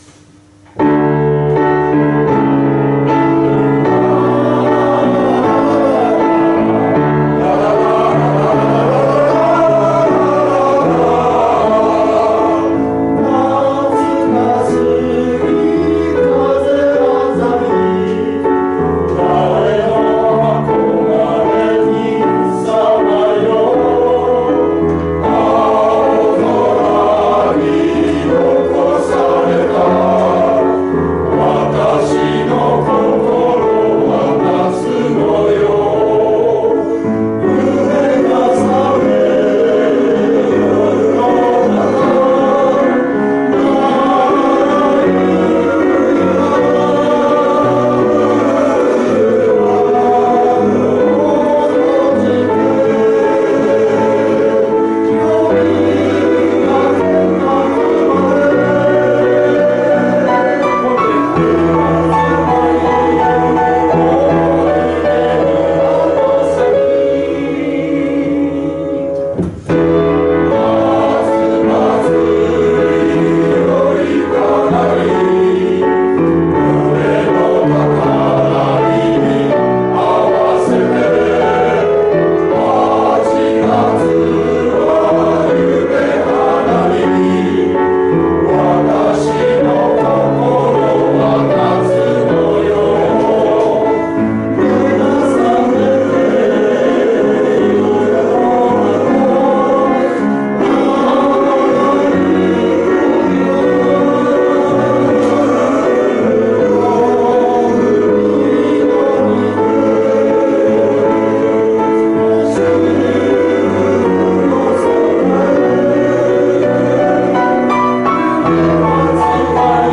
最後に「少年時代」 「高原列車は行く」を、通して歌いました。